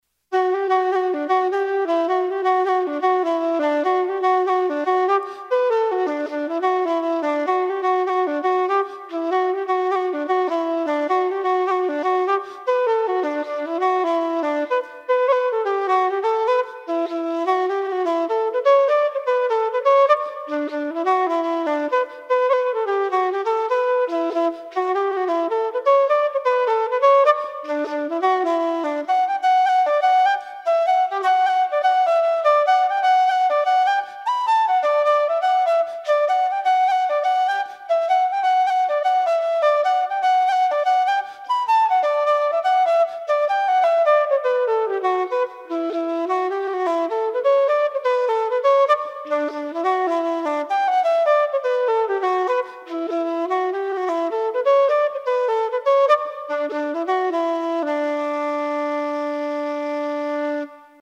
EATMS Tune book recording
56FoxhuntersJig_EATMS.mp3